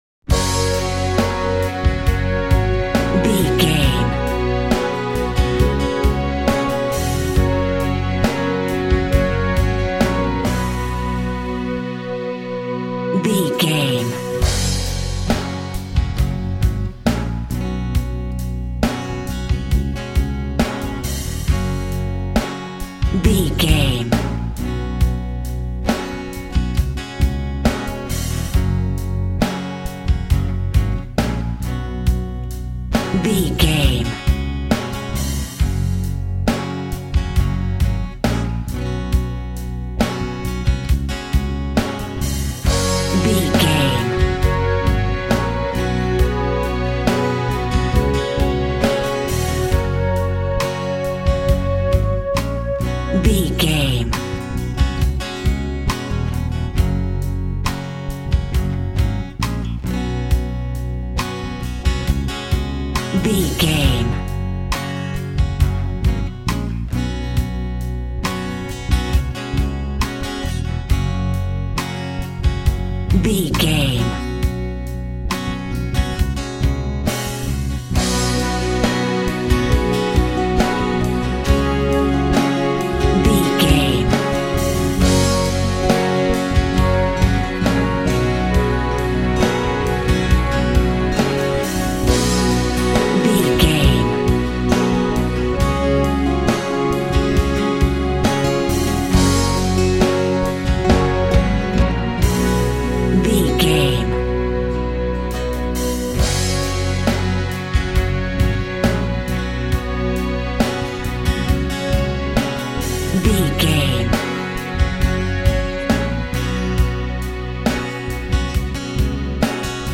Ionian/Major
pop
cheesy
pop rock
synth pop
drums
bass guitar
electric guitar
piano
hammond organ